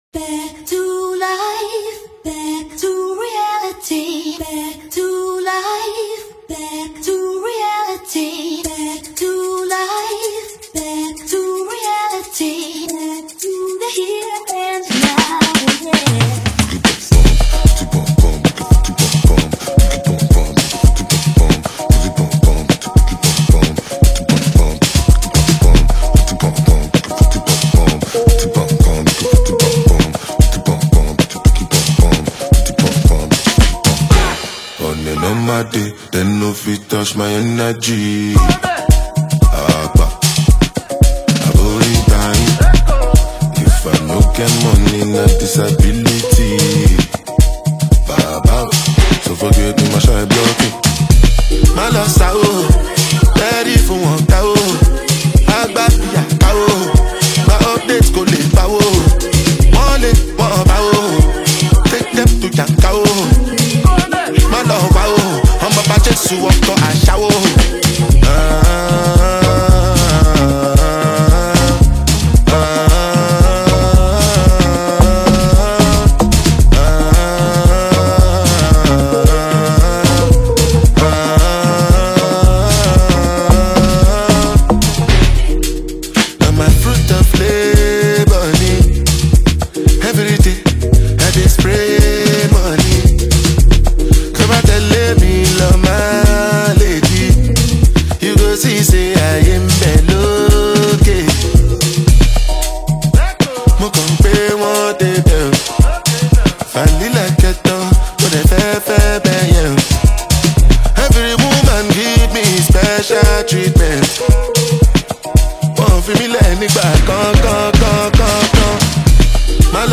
A streetwise anthem with pulsating beats and sharp bars.